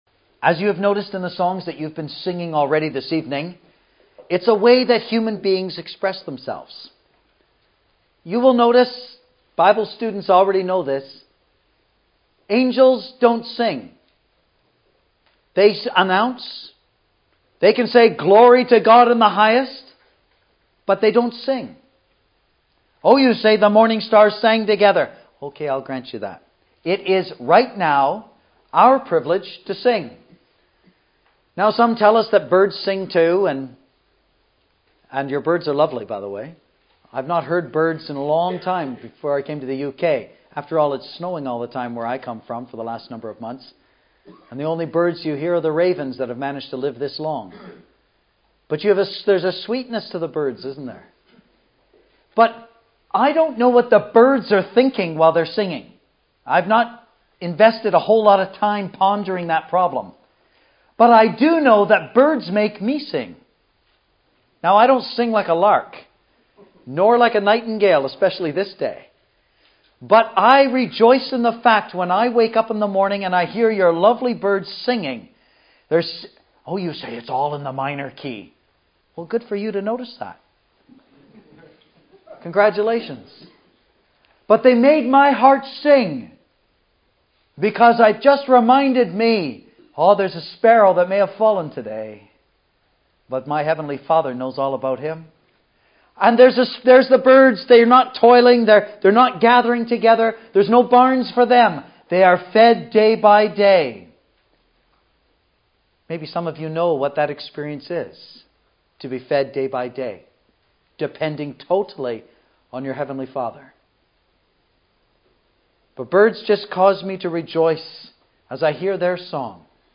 2012 Easter Conference